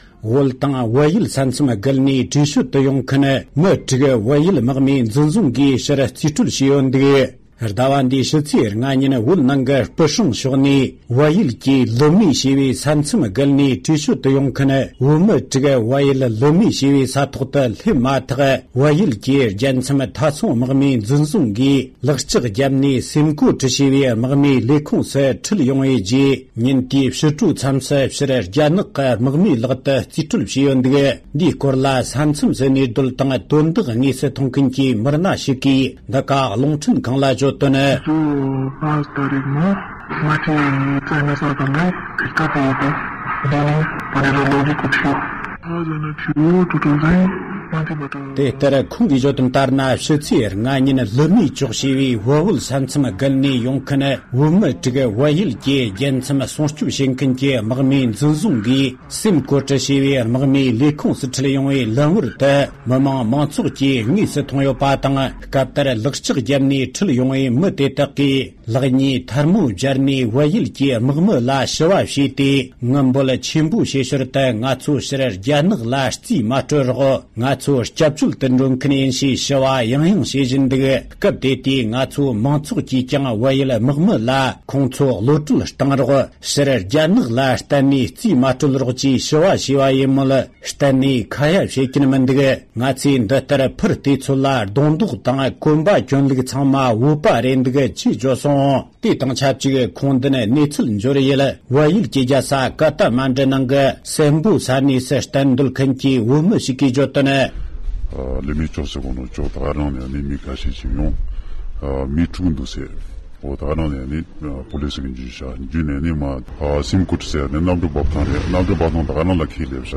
ས་གནས་ནས་བཏང་བའི་གནས་ཚུལ།
སྒྲ་ལྡན་གསར་འགྱུར། སྒྲ་ཕབ་ལེན།